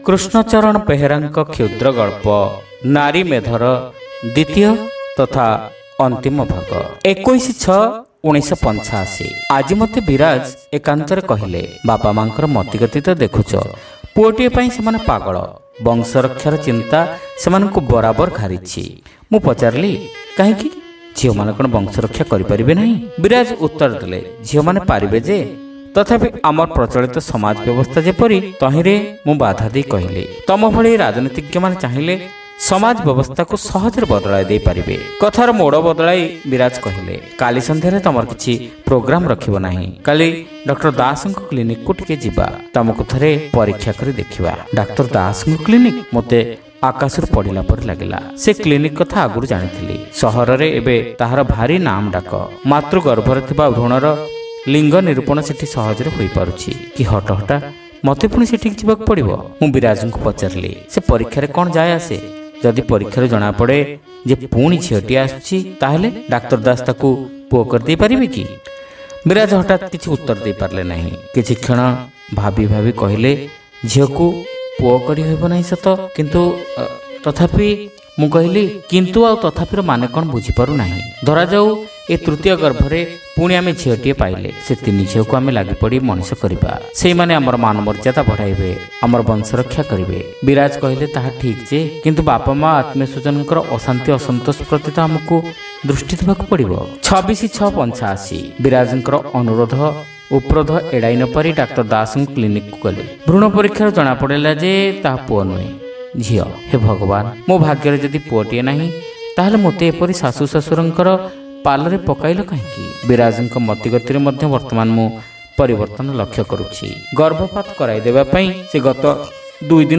Audio Story : Narimedha (Part-2)